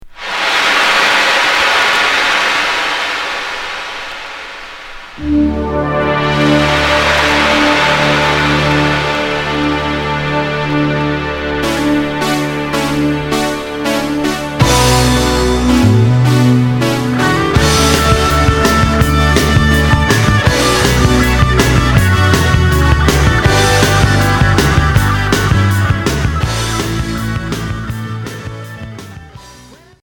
Hard FM Unique 45t retour à l'accueil